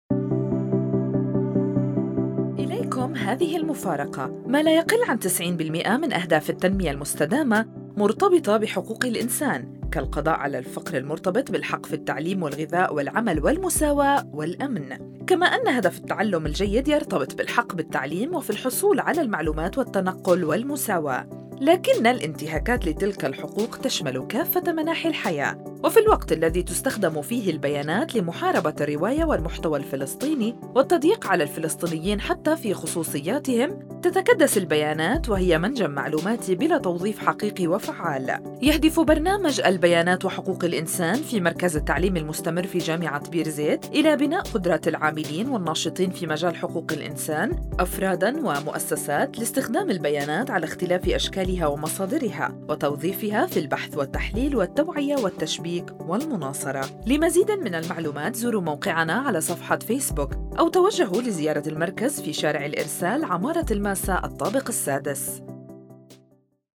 Radio Spot 1